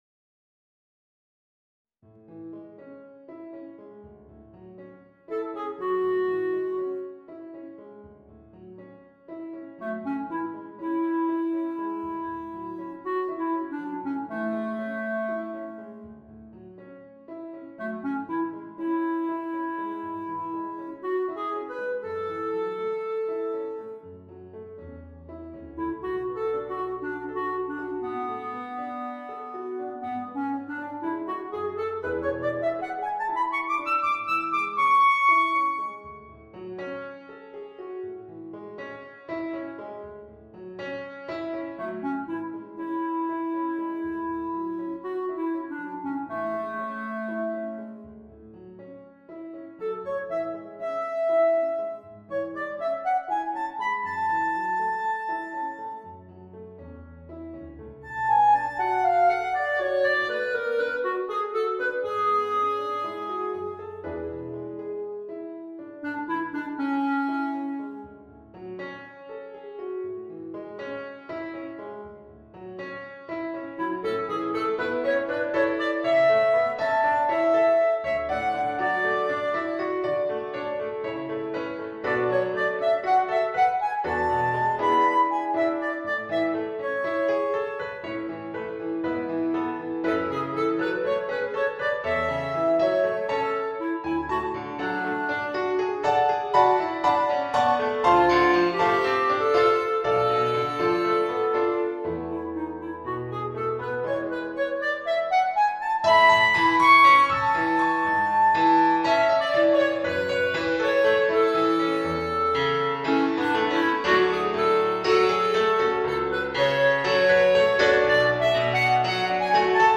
Clarinet & Piano